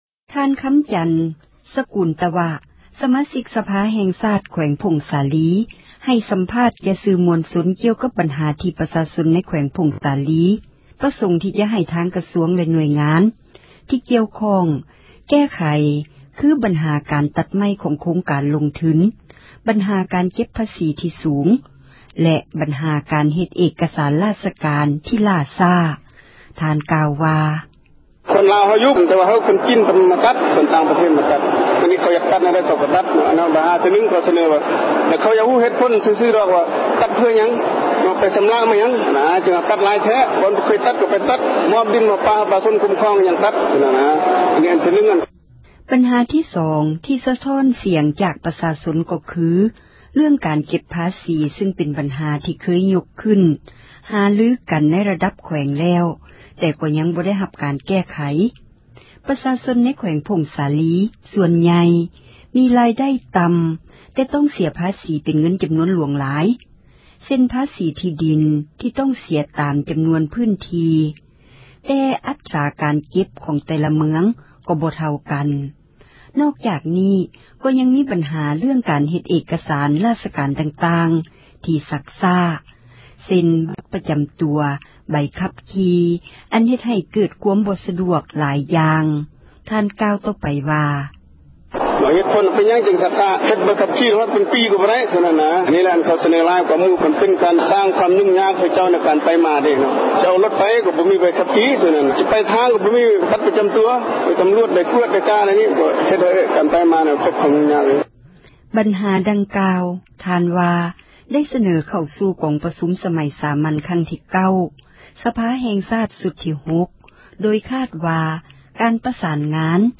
ທ່ານ ຄໍາຈັນ ສະກູລຕະວະ ສະມາຊິກ ສະພາແຫ່ງຊາດ ແຂວງຜົ້ງສາລີ ໃຫ້ສັມພາດ ແກ່ສື່ມວນຊົນ ກ່ຽວກັບບັນຫາ ທີ່ປະຊາຊົນ ໃນແຂວງ ຜົ້ງສາລີ ປະສົງທີ່ຈະໃຫ້ ທາງກະຊວງແລະ ໜ່ວຍງານທີ່ກ່ຽວຂ້ອງ ທໍາການແກ້ໄຂ ຄື: ບັນຫາການຕັດໄມ້ ຂອງໂຄງການລົງທຶນ ບັນຫາການເກັບ ພາສີທີ່ສູງ ແລະ ບັນຫາການເຮັດ ເອກກະສານ ຣາຊການ ທີ່ລ່າຊ້າ: